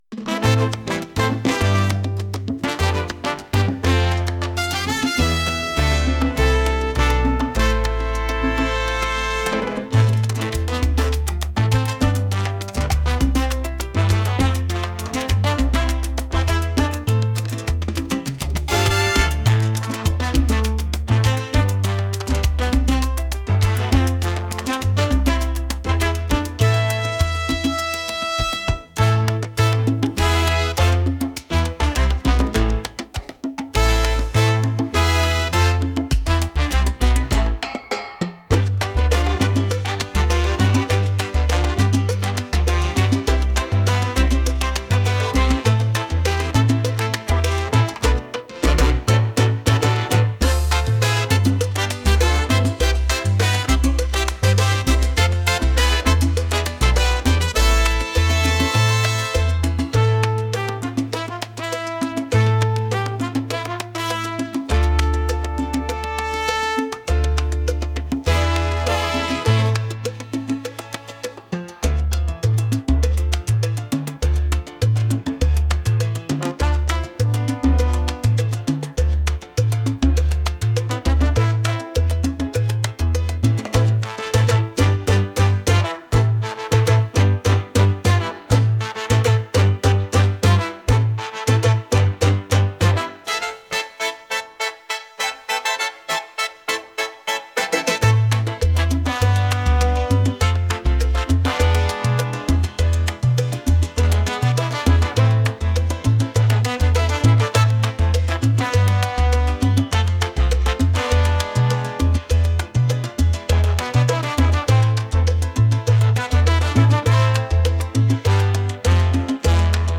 latin | energetic